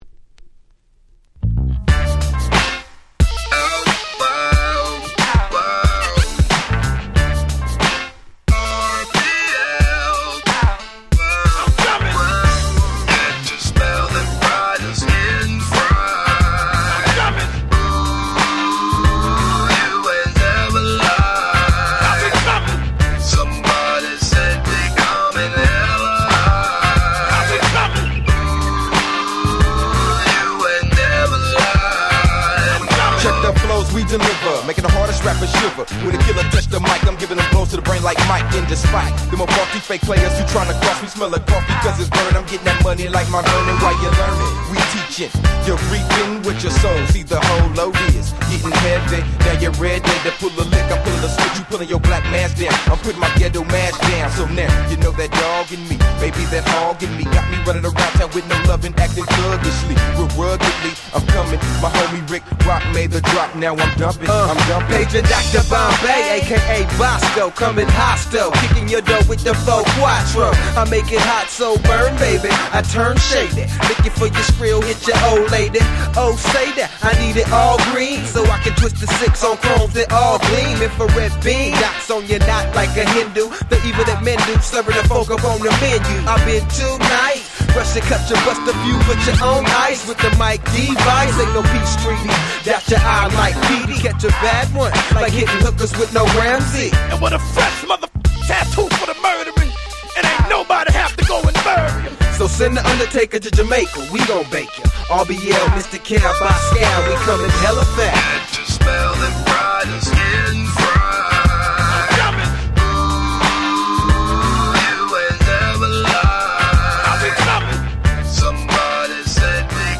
97' West Coast Hip Hop人気盤！！
ウエッサイウエスト Gangsta Rap ギャングスタラップミスティカル トークボックス G-Rap